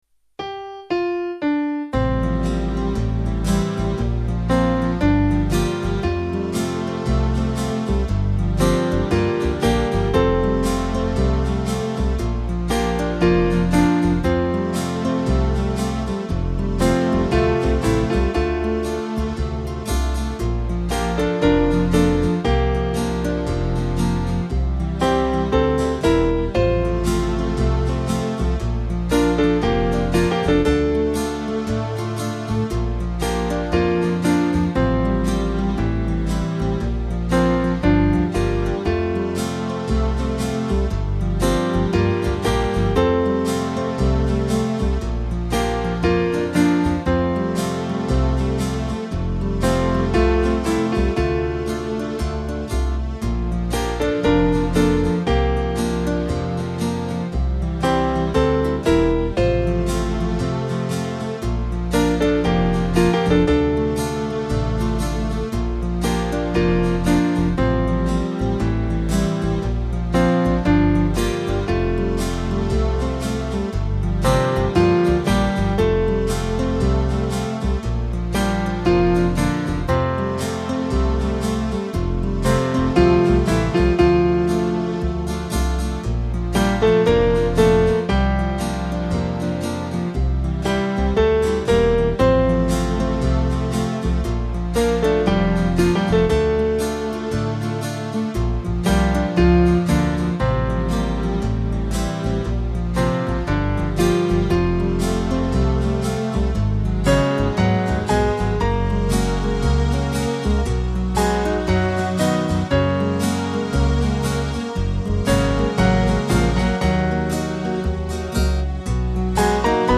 Small Band
Slight lilt